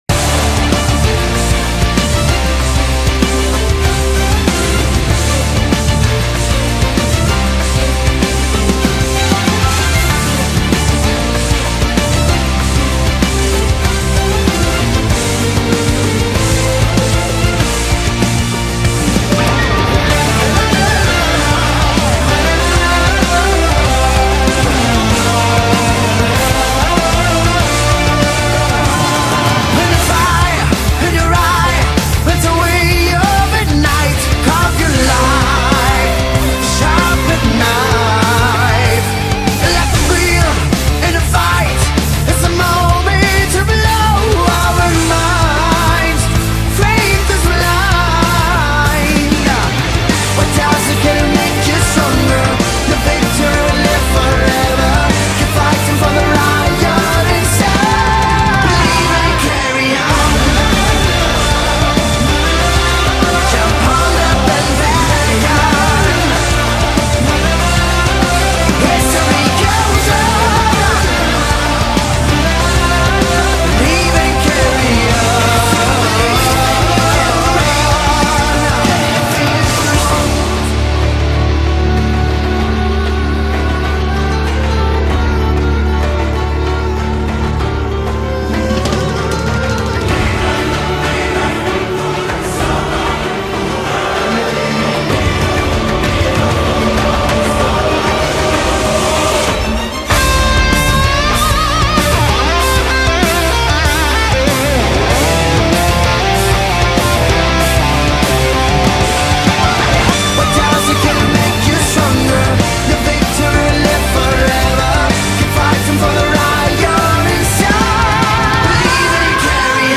BPM96
Audio QualityPerfect (High Quality)
Time for Arab folk/Prog/Power Metal fusion.